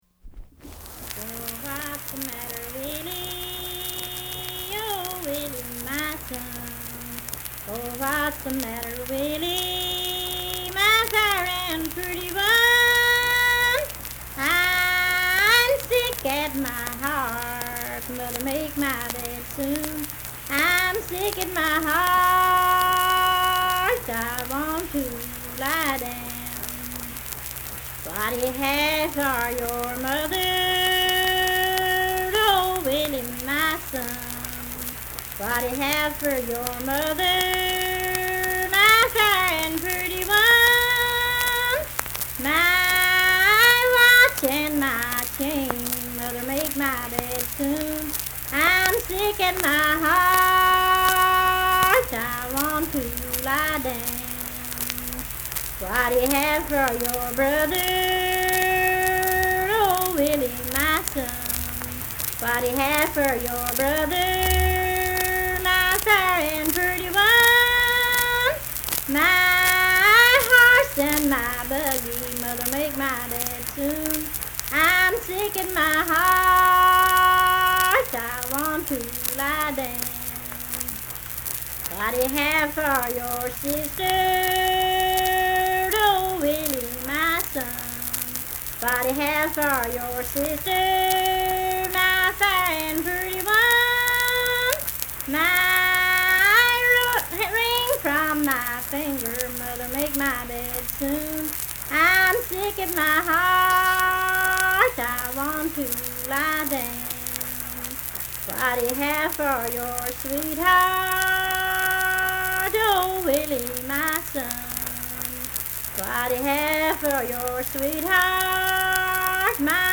Unaccompanied vocal music
Verse-refrain, 5(8w/R).
Voice (sung)